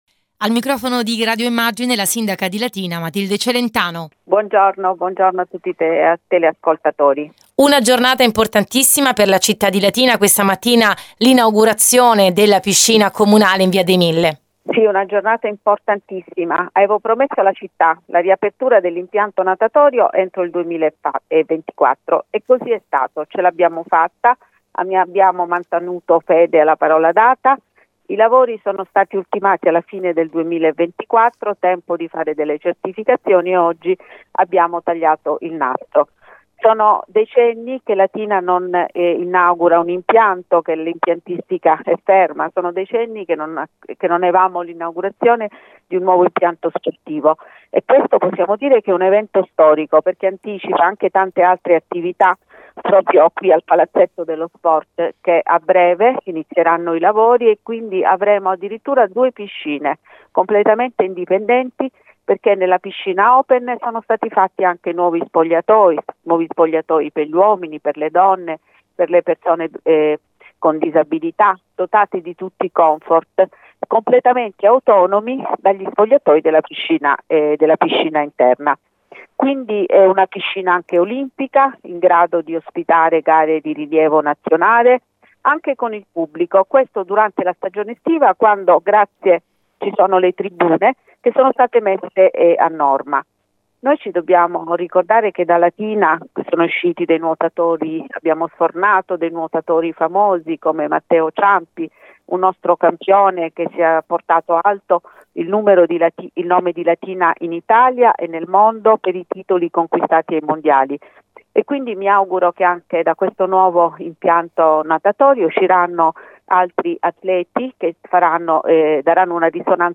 La Sindaca Matilde Celentano
sindaco_piscina.mp3